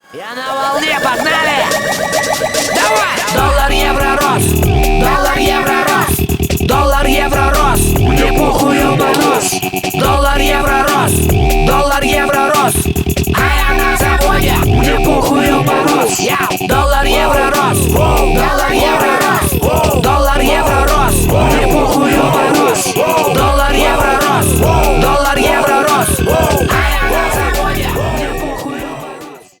Внимание Ненормативная лексика!
Рэп и Хип Хоп